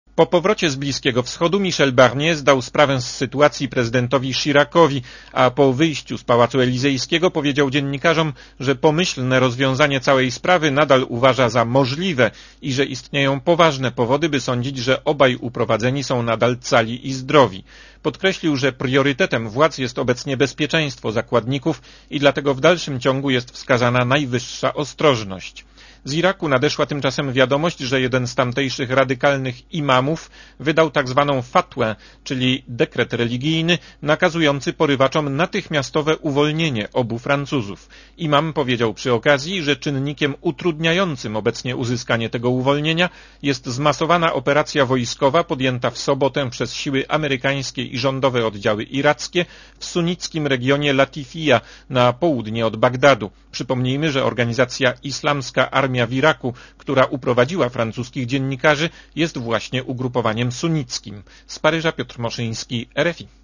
relacji